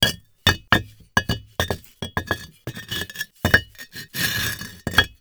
CONSTRUCTION_Bricks_Medium_loop_mono.wav